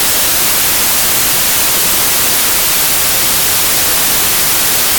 Um ruído branco, por exemplo, gerado por um processo aleatório uniformemente distribuído, é informacionalmente sempre original (novo), porém para a nossa percepção auditiva, este soa sempre igual.
Acima tem-se um arquivo áudio de um ruído branco, criado através de um gerador de números aleatórios. A figura acima mostra um pequeno trecho deste mesmo áudio onde pode-se observar a ausência de um padrão (ou periodicidade), tornando-o acusticamente desprovido de padrão mas auditivamente com um padrão constante e facilmente identificável.